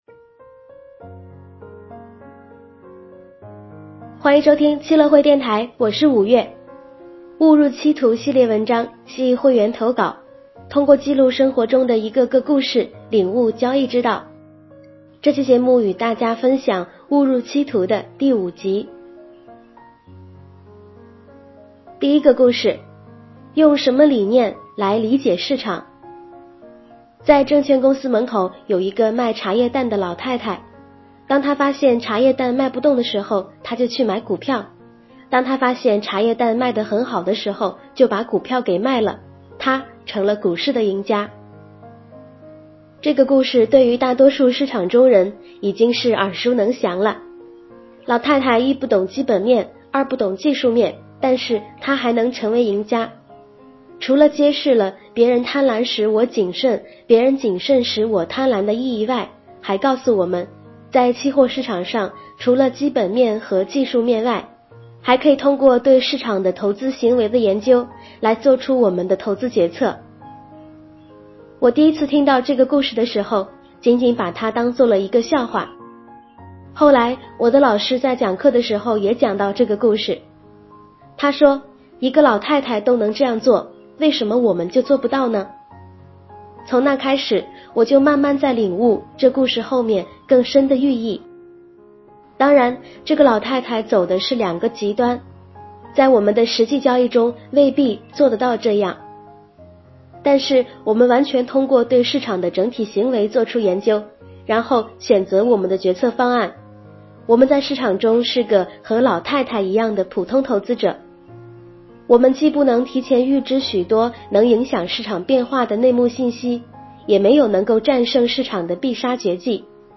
悟入期途（五）：用什么理念来理解市场（有声版） - 期乐会